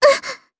Kirze-Vox_Damage_jp_02.wav